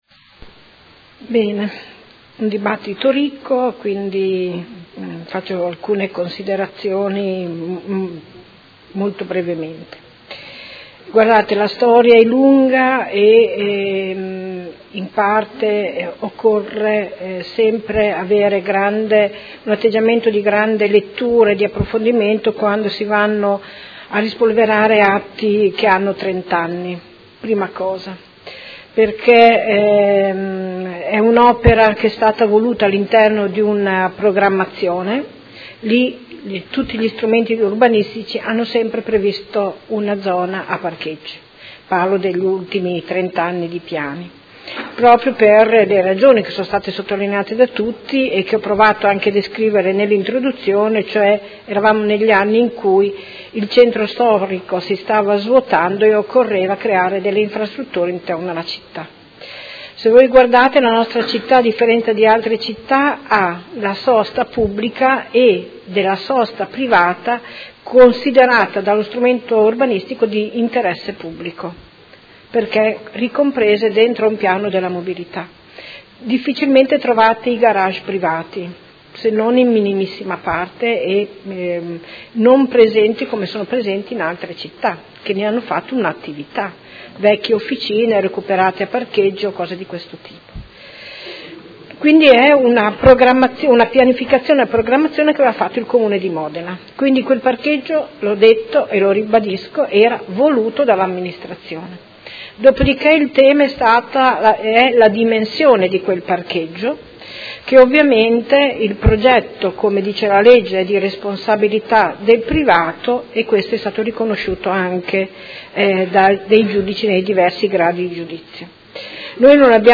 Seduta del 20/12/2018.